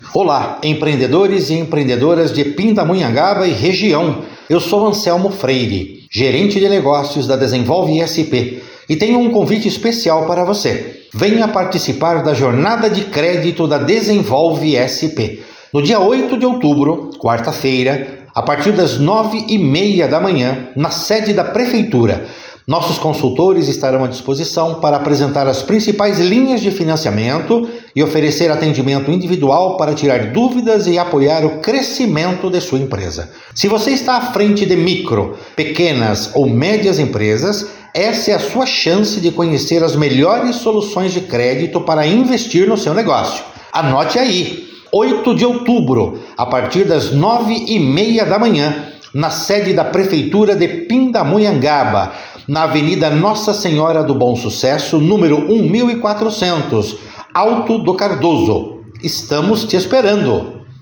Convite por áudio